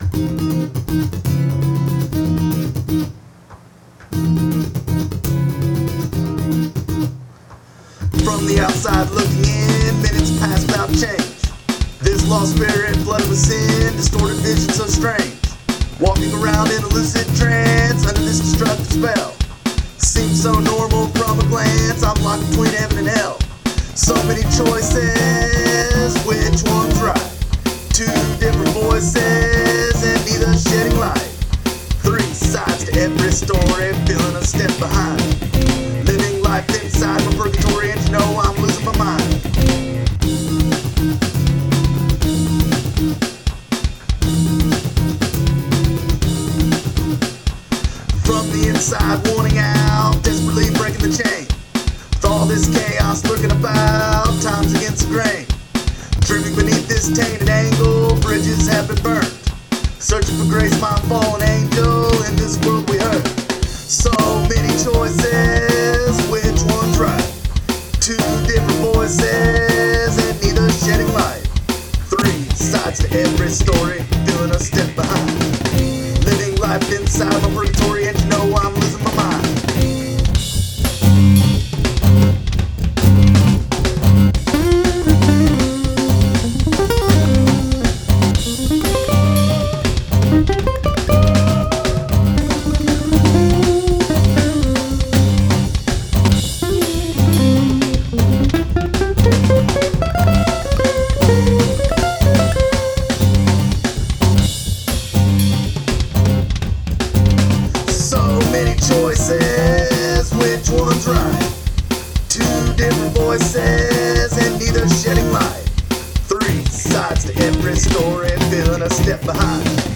I added music courtesy of GarageBand in 09. I had no mic, only the one on top of my old iMac. Full of liquid courage I laid down a vocal.